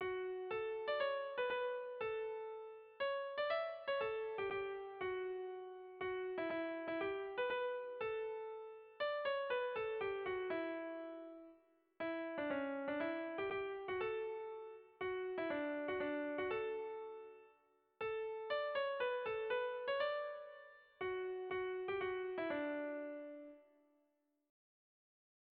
Erlijiozkoa
Gabika < Ereño < Busturialdea < Bizkaia < Euskal Herria
A-B-C-D